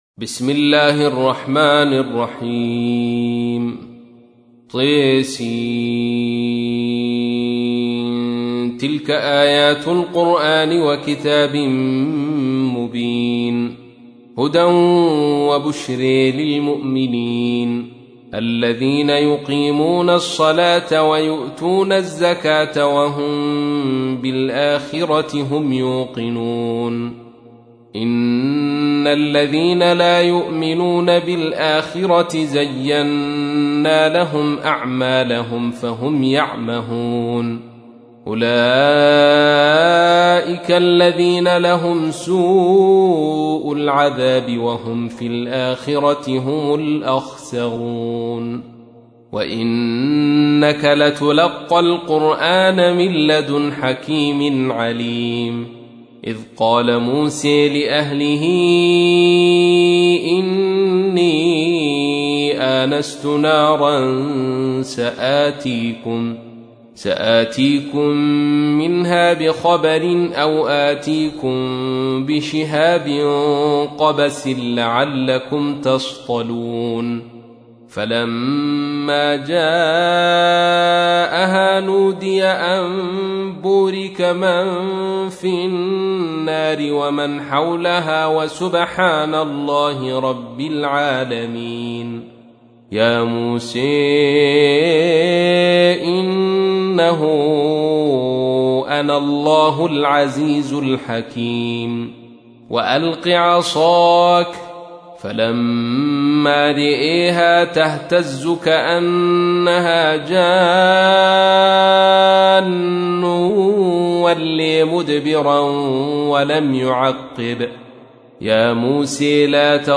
تحميل : 27. سورة النمل / القارئ عبد الرشيد صوفي / القرآن الكريم / موقع يا حسين